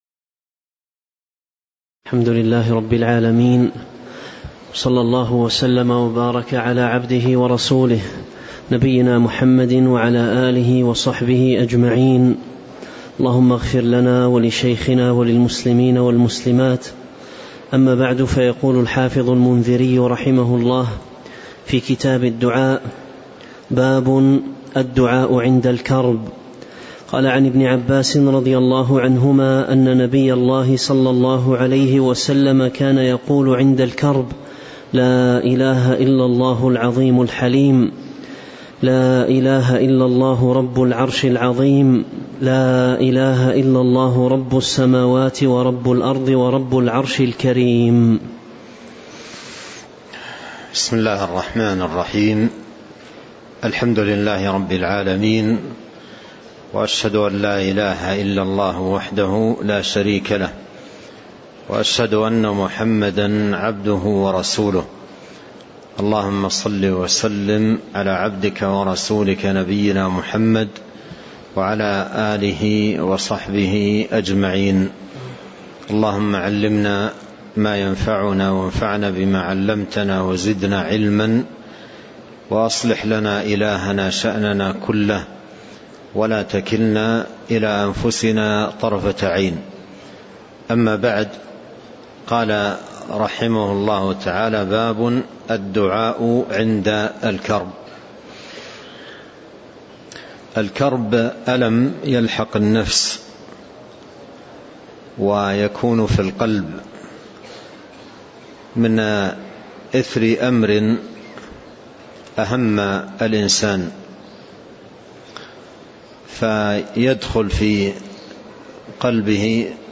تاريخ النشر ١ ذو الحجة ١٤٤٣ هـ المكان: المسجد النبوي الشيخ